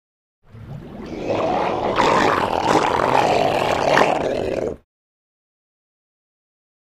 Seal, Elephant Call, Cry. Cub Growls, Snorts Multiple Times With Ocean And Seagulls In Background. Close Perspective.